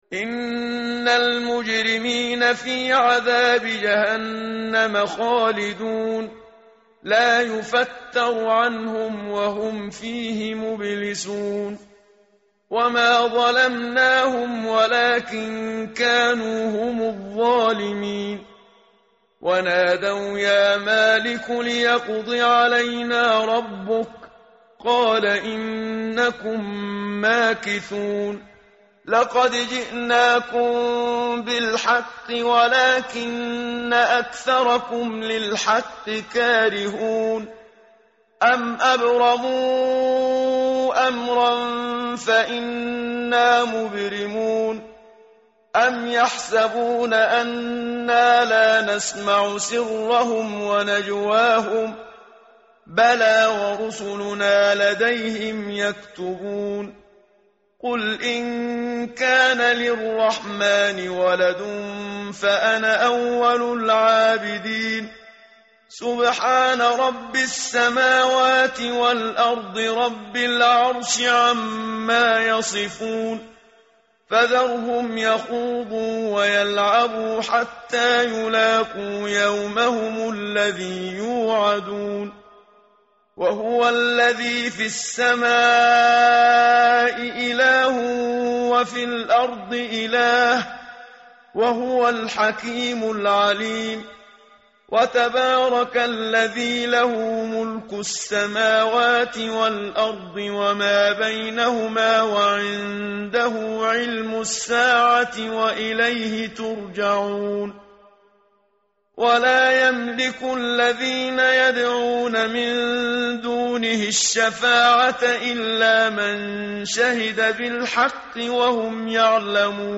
متن قرآن همراه باتلاوت قرآن و ترجمه
tartil_menshavi_page_495.mp3